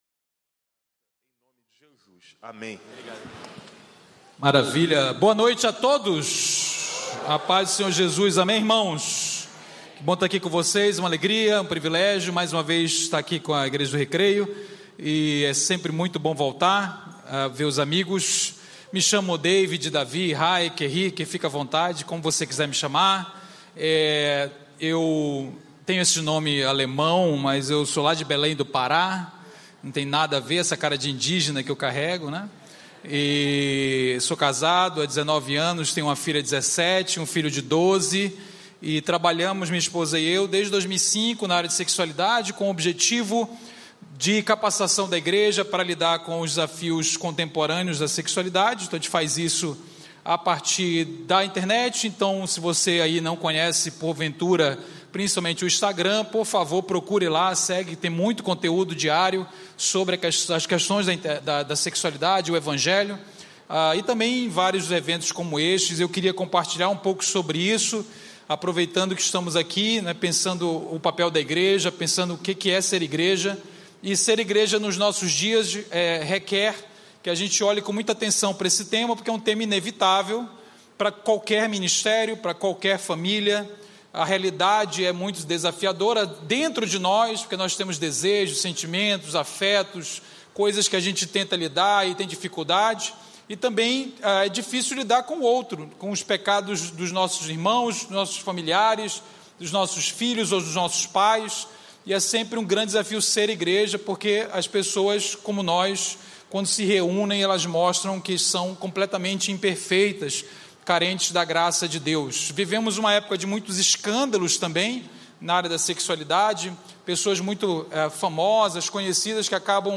YouthCon 2026 #4